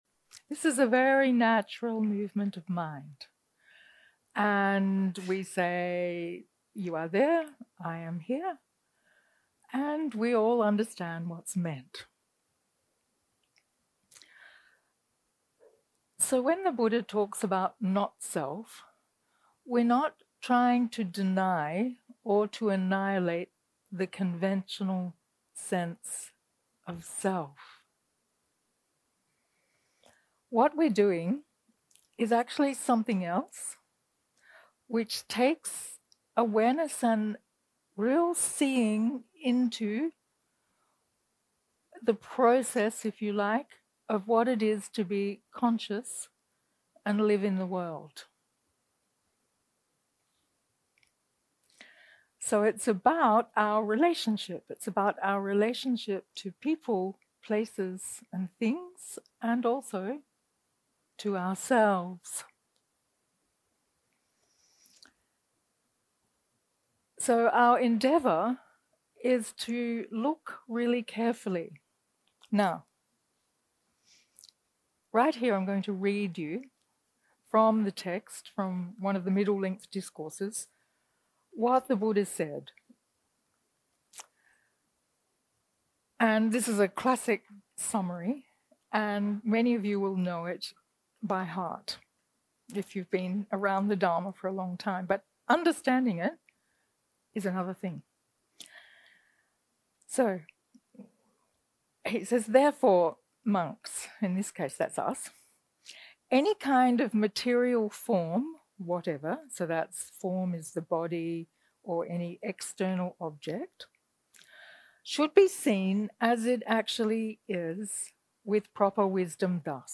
יום 2 – הקלטה 4 – ערב – שיחת דהארמה – Our Habit of Experiencing Life Dualistically.
0:00 0:00 סוג ההקלטה: Dharma type: Dharma Talks שפת ההקלטה: Dharma talk language: English